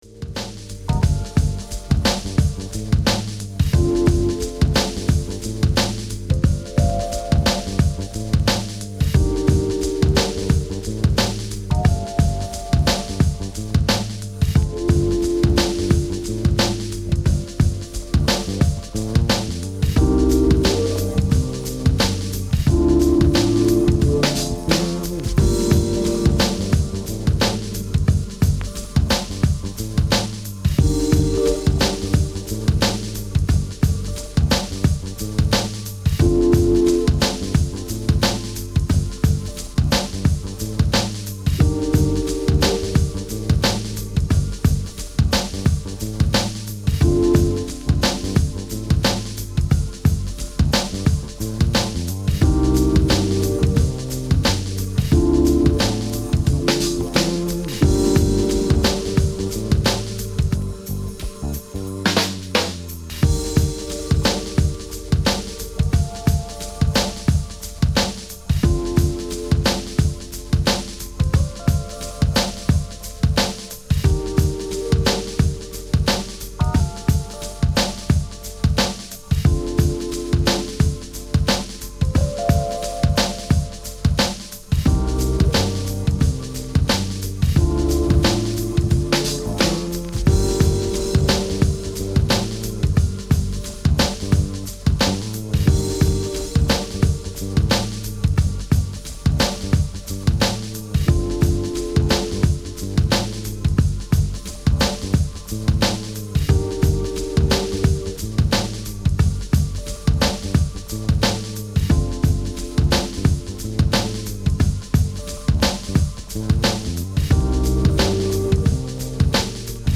心地よく弧を描くようなシンセワークが魅力的です。モダンフュージョンのテイストも伺える、当店マスターピース。